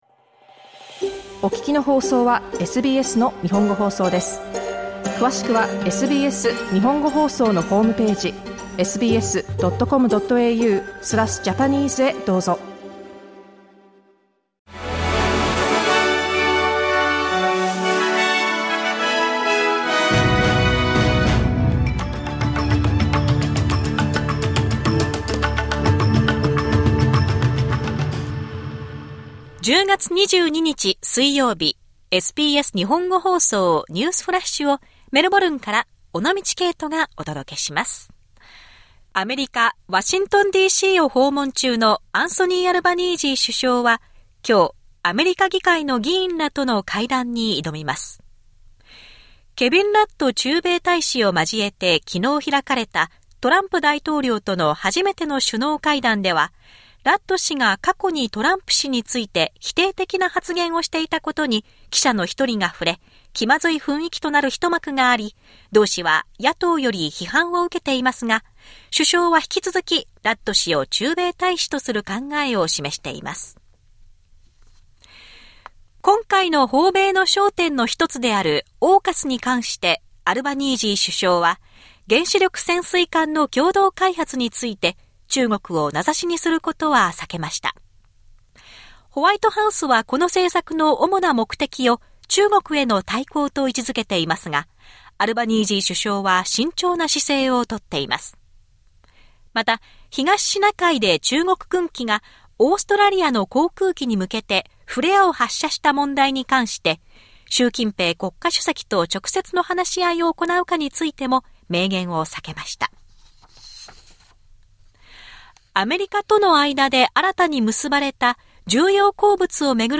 SBS日本語放送ニュースフラッシュ 10月22日 水曜日